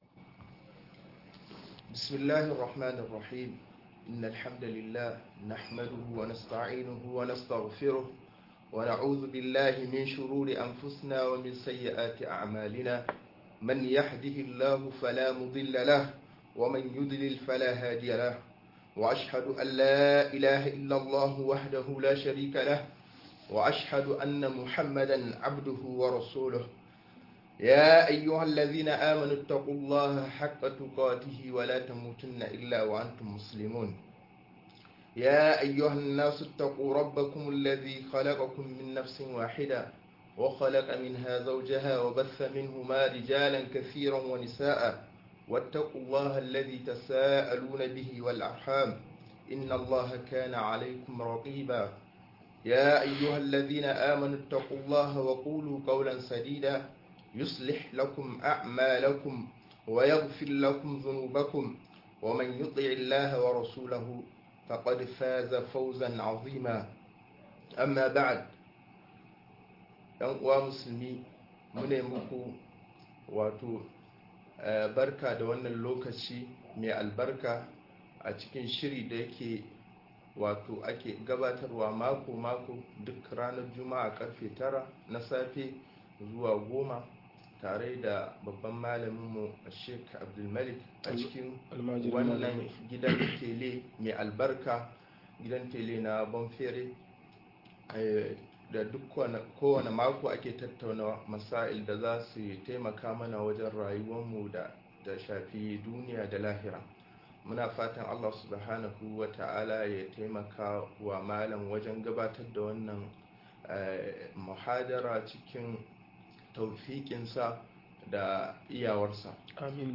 Hanyoyin yin tasiri ga al'umma-01 - MUHADARA